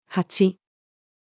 八-female.mp3